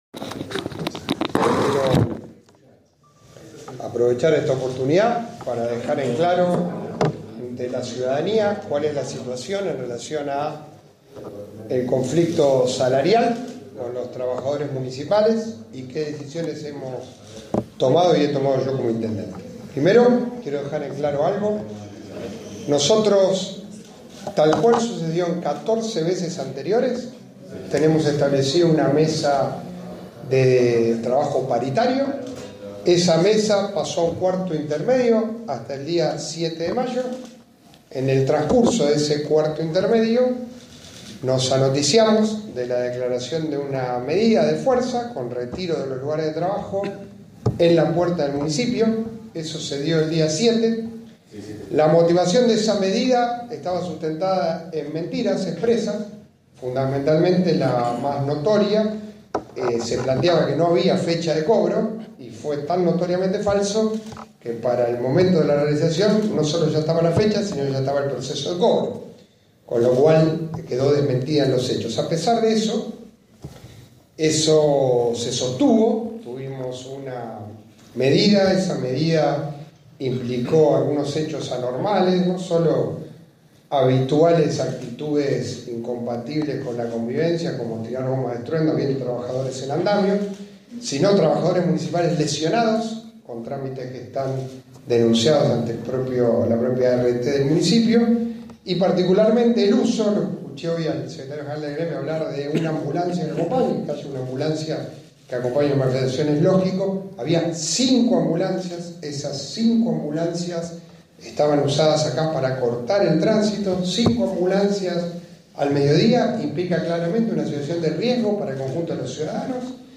FOTO: El intendente realizó el anuncio en conferencia de prensa.